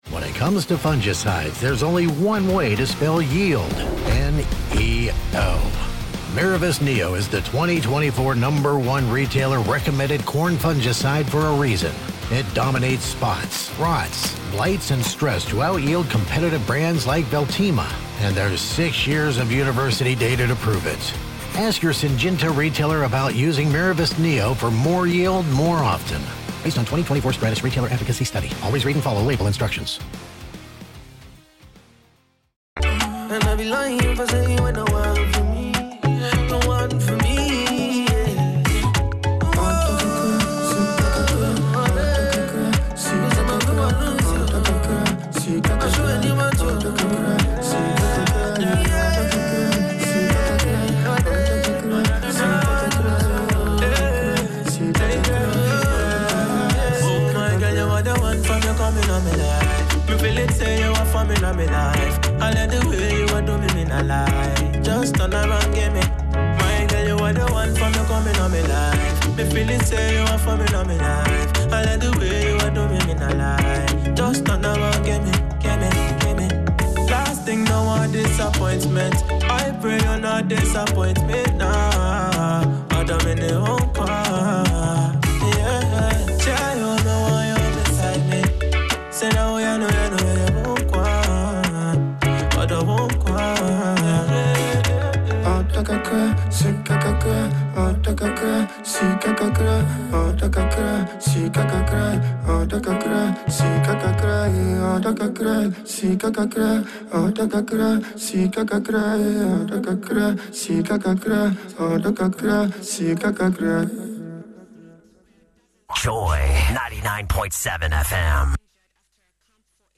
Discussion and analysis of the topmost news story of the day … continue reading 260 епізодів # Daily News # Politics # News Talk # News # Government # Multimedia Ghana